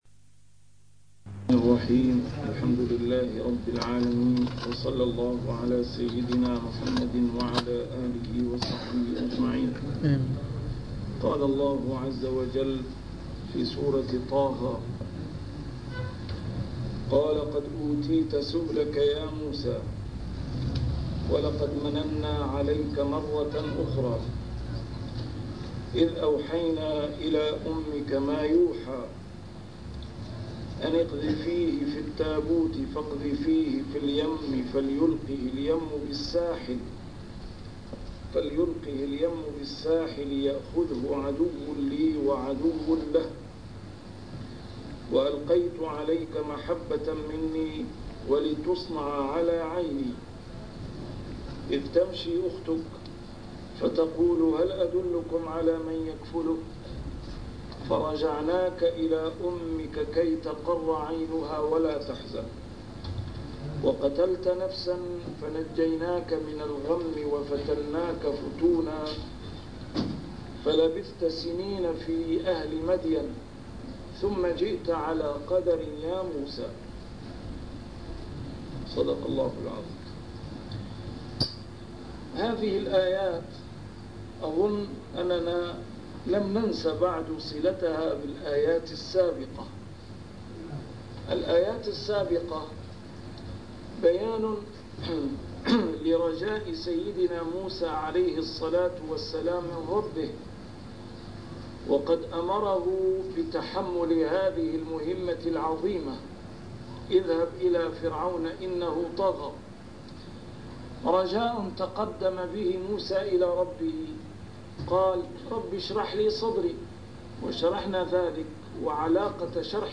A MARTYR SCHOLAR: IMAM MUHAMMAD SAEED RAMADAN AL-BOUTI - الدروس العلمية - تفسير القرآن الكريم - تسجيل قديم - الدروس 60: طه 036-040